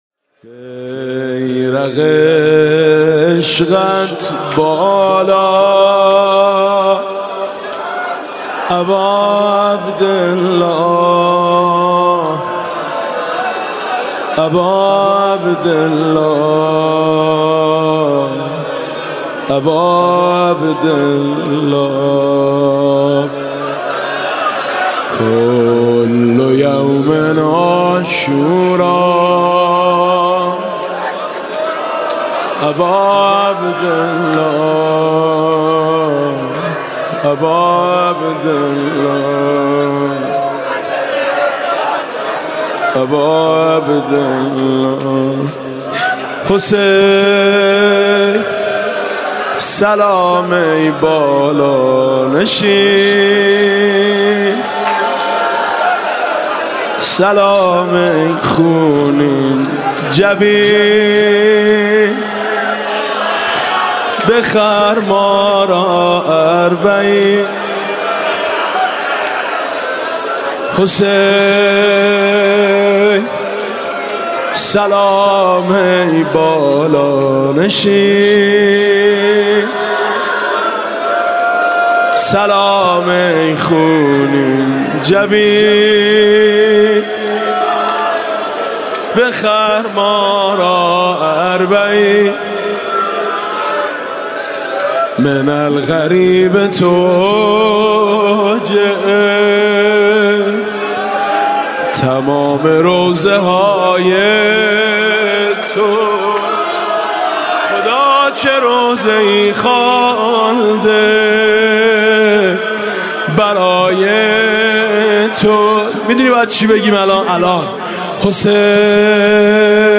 ظهر عاشورا محرم 96 - هیئت میثاق - روضه عصر عاشورا